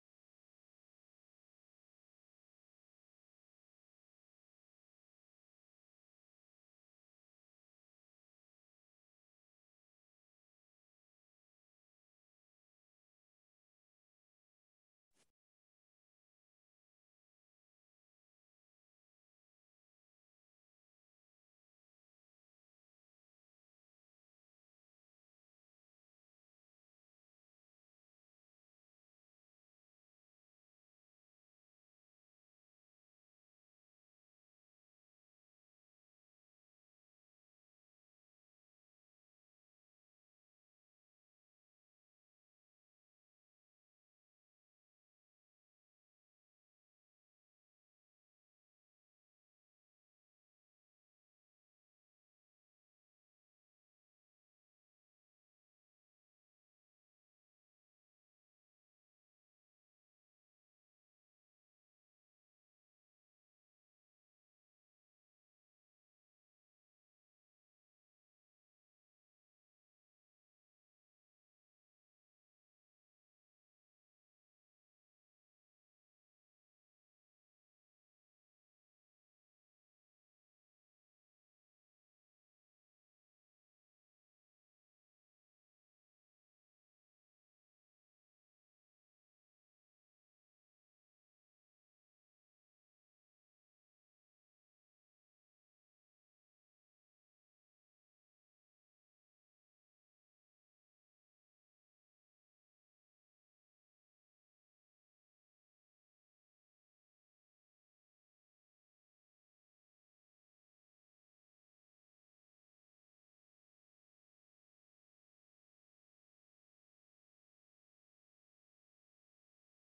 Cours de Pensée Juive sur la Paracha Vayichlah